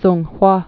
(snghwä) also Sun·ga·ri (snggə-rē)